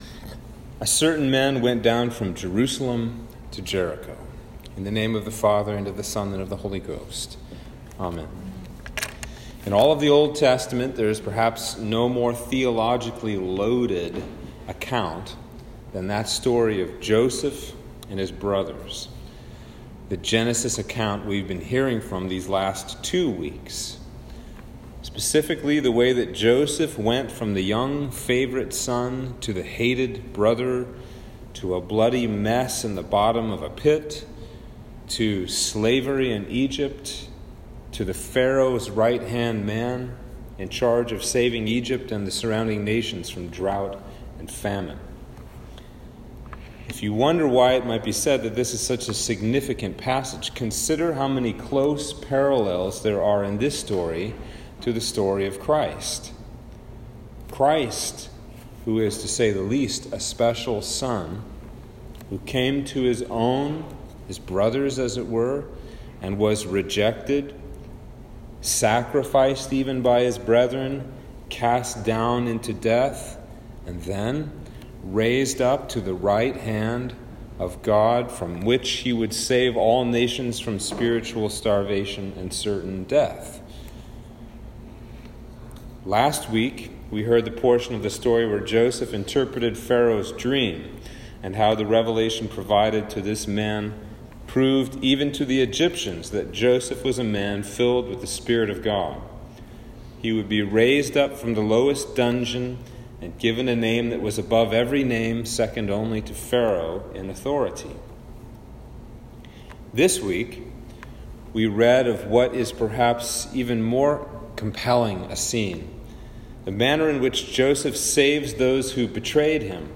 Sermon for Trinity 13 - 2021